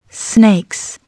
snakes00.wav